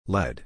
A pronúncia nos dois sentidos também é diferente. lead como substantivo é pronunciado como léd.